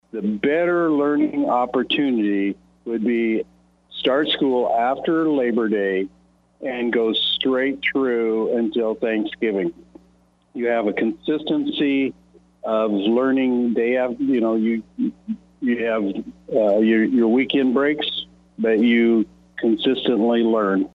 Rhiley prefiled House Bill 2409 that would stop Kansas school districts from starting before the Labor Day holiday. The former teacher tells KSAL News he believes less breaks and a consistent schedule is the key to learning.